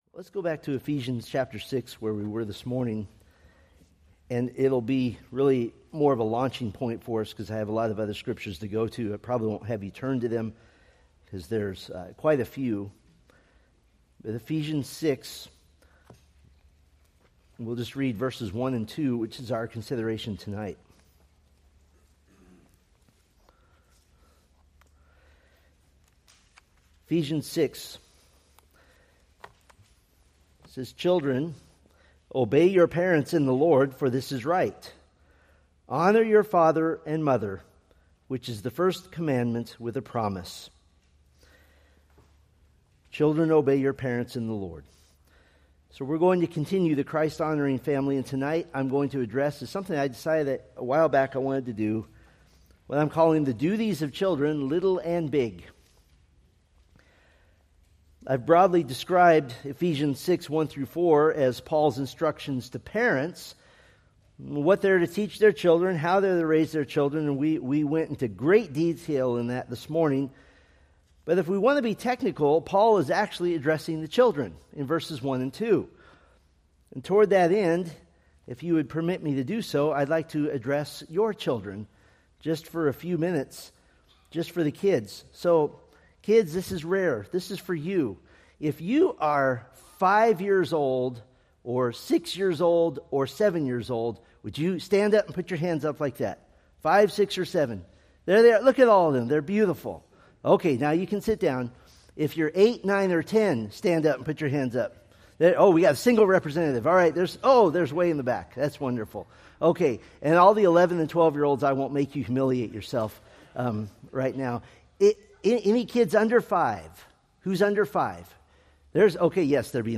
Preached August 24, 2025 from Selected Scriptures